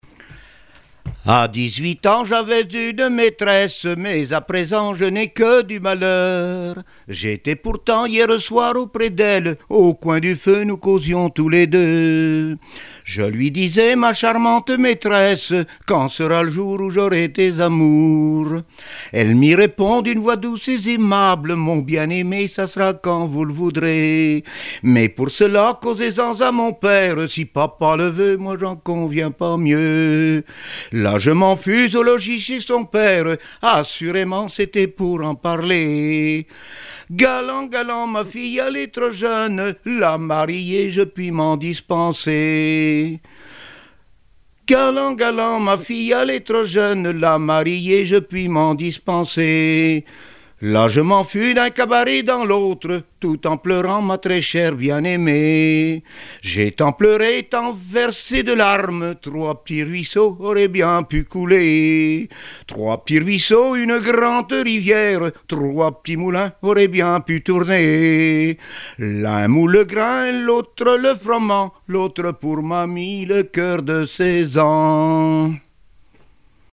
Saint-Hilaire-de-Riez
Genre strophique
Répertoire de chansons populaires et traditionnelles
Pièce musicale inédite